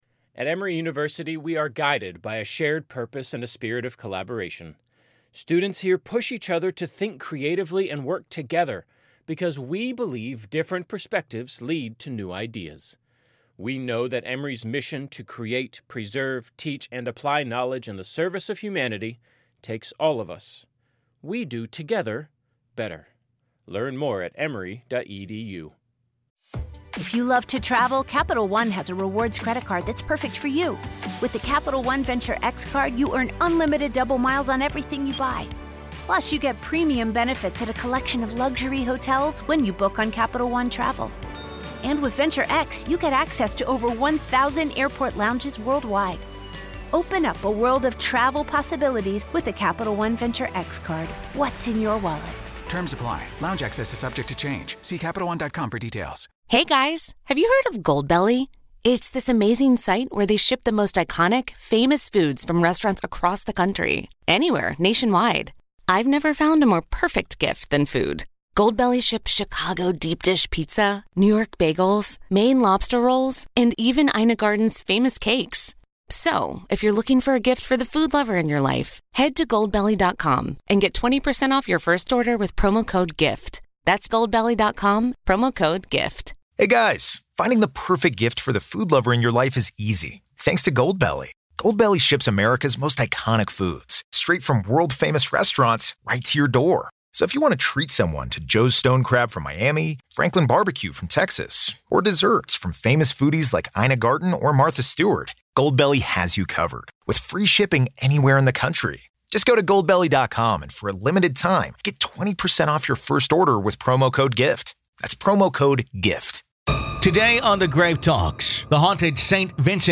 In today’s episode of The Grave Talks, we talk with paranormal investigator